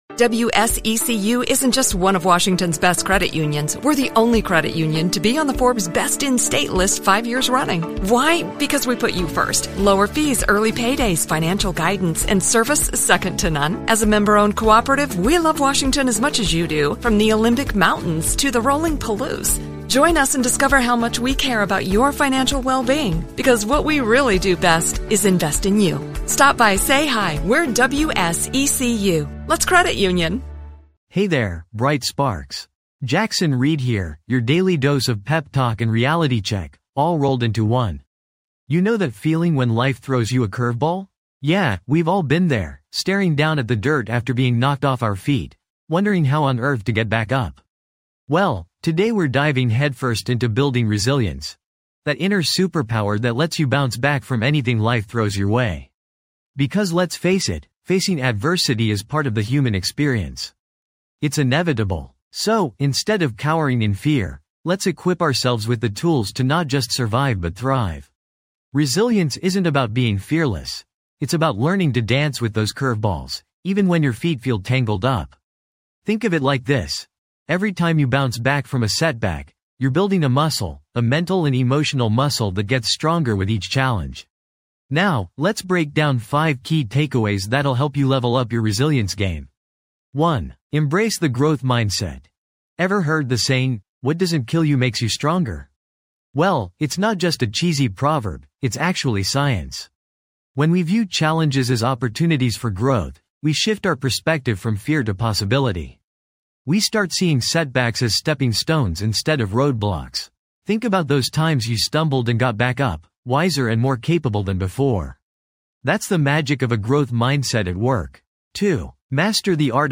Personal Development Motivation Inspiration Self-Help Empowerment
This podcast is created with the help of advanced AI to deliver thoughtful affirmations and positive messages just for you.